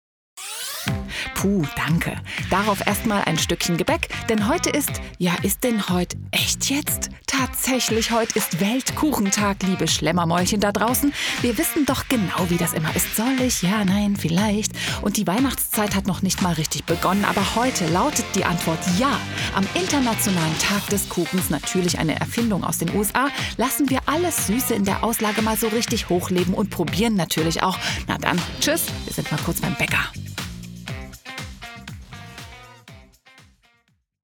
Erfahrene Profisprecherin
RTL - Off Voice
5-RTL-Fun-Spots-I-©RTL-Group.mp3